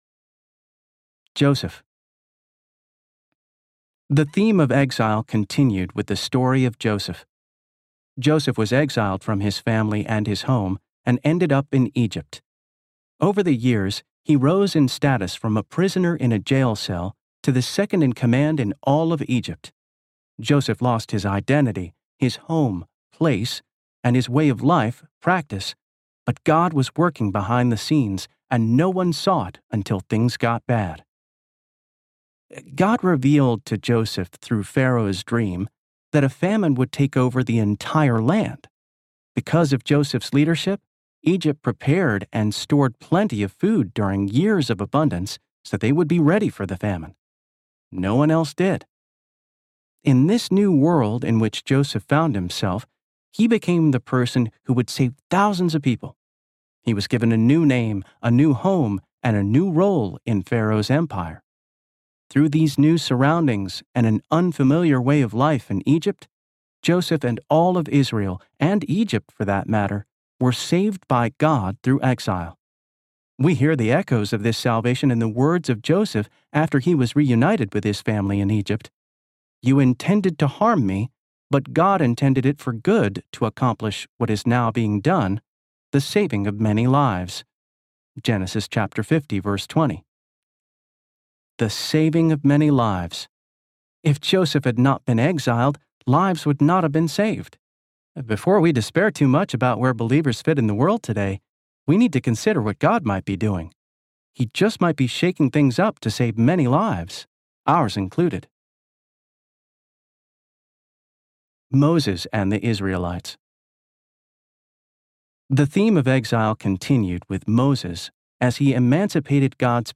Faith for This Moment Audiobook
4.62 Hrs. – Unabridged